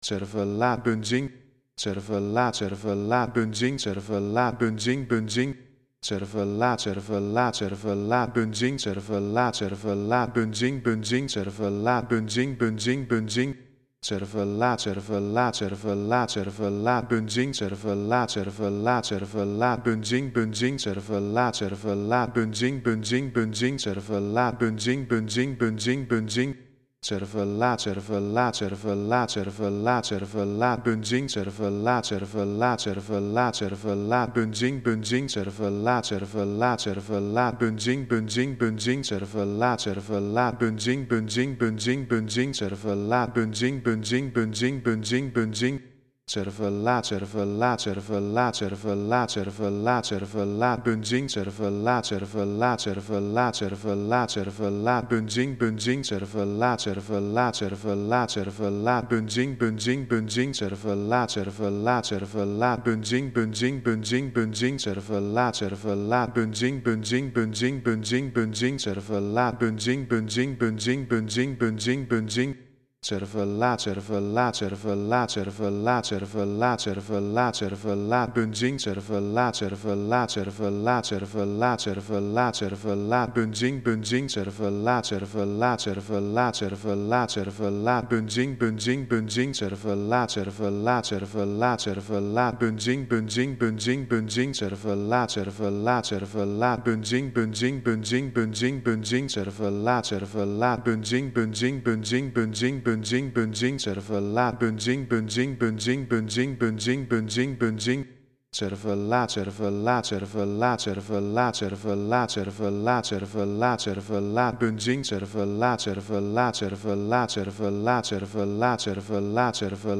Voor degenen die niet zo vaak in mijn ‘Geluid‘-sectie komen: repetabsen (een samensmelting van de woorden repetitief en abstract – ik zei al, sorry) zijn klankdichten gebaseerd op herhaling, hevig geïnspireerd op de vroegere werken van über-repetist Philip Glass.